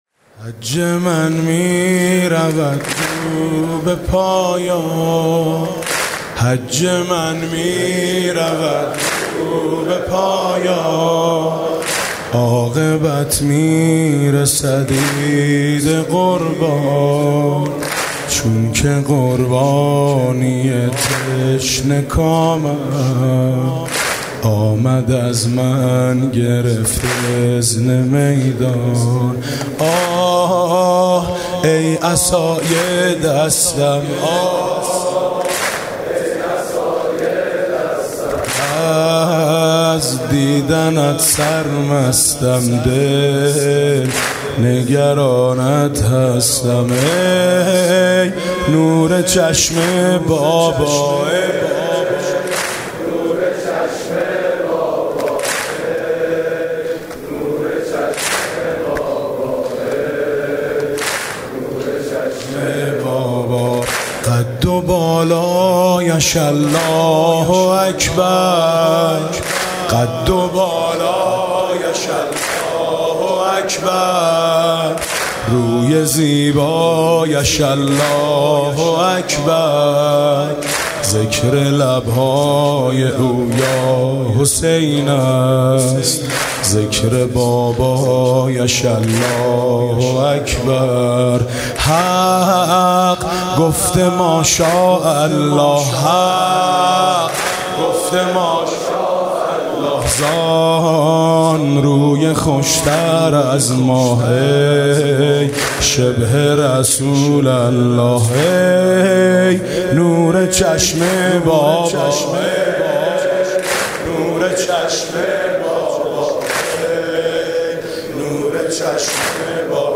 به گزارش خبرنگار فرهنگی خبرگزاری تسنیم، هشتمین شب عزاداری برای مصایب حضرت اباعبدالله الحسین علیه السلام در هیأت "آیین حسینی" برگزار شد. این هیأت امسال در مجتمع فرهنگی ورزشی امام رضا(ع) واقع در منطقه 22 تهران شهرک گلستان از ساعت 22 برگزار می‌شود.